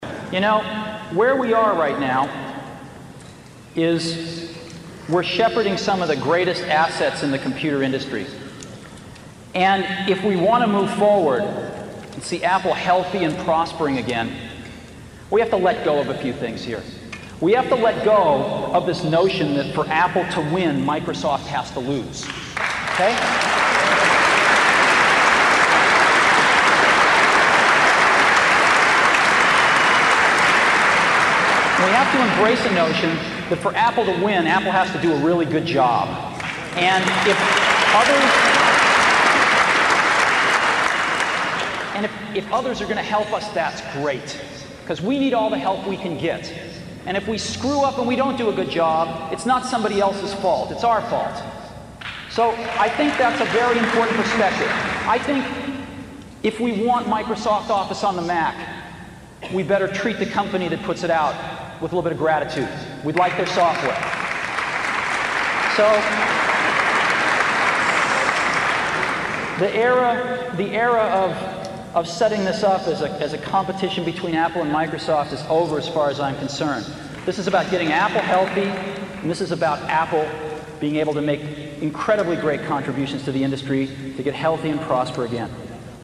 财富精英励志演讲92:在疯狂中我们看到了天才(12) 听力文件下载—在线英语听力室